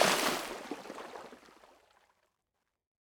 small-splash-3.ogg